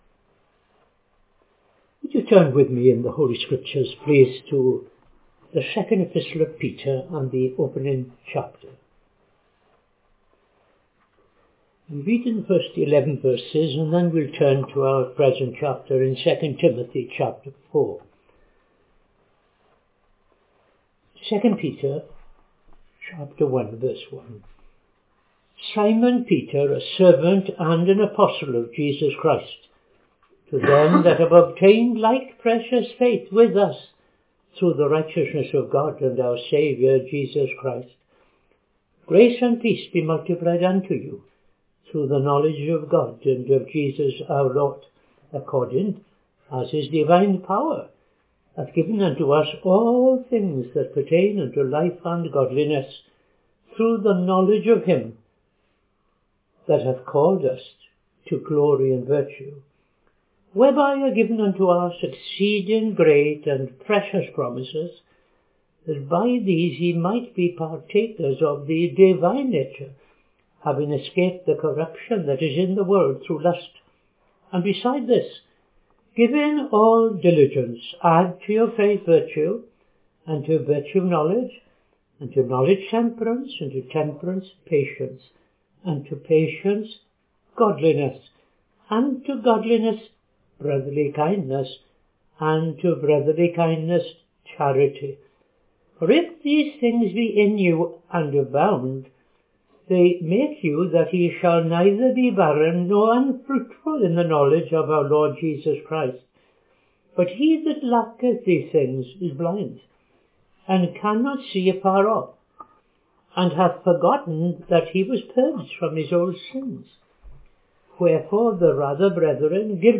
Reading II Peter 1:1-11; II Timothy 4:16-18